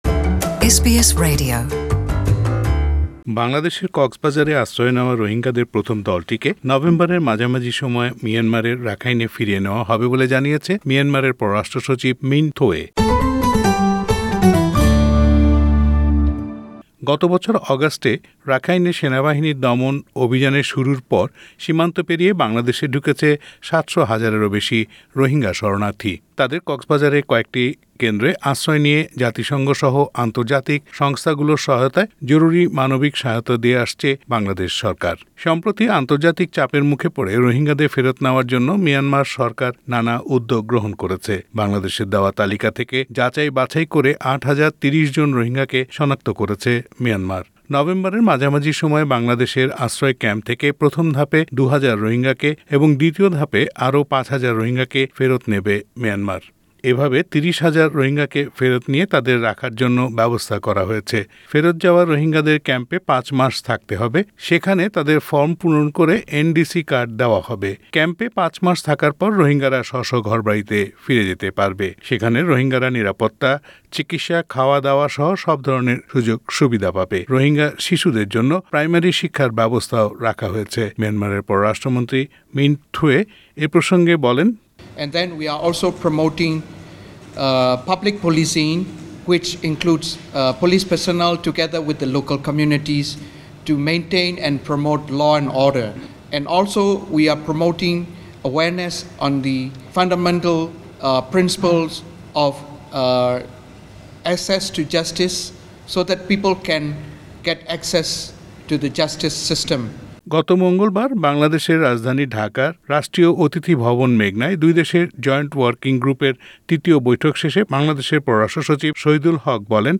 Listen to the report in Bangla in the audio player above.